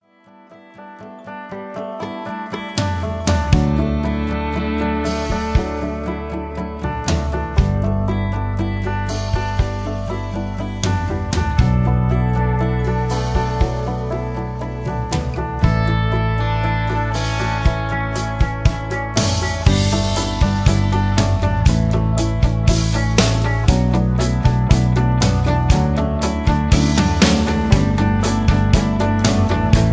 mp3 backing tracks